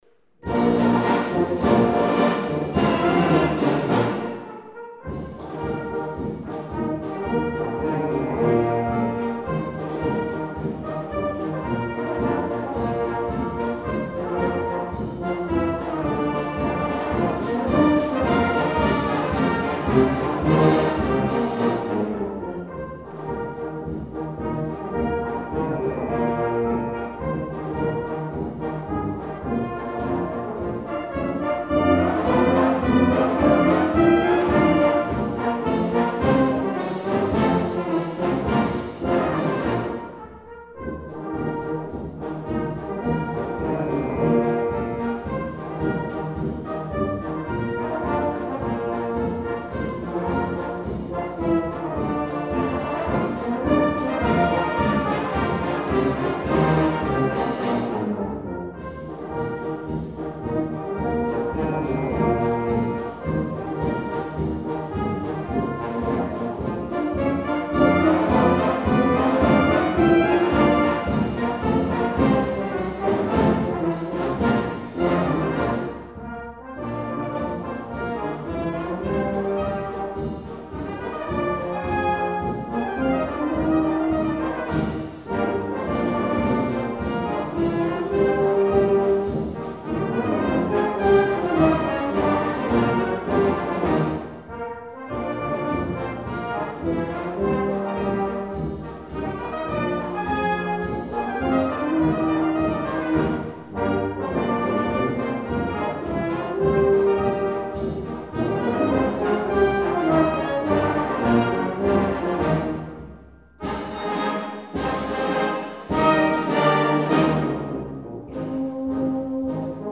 für Brass Band oder Harmonie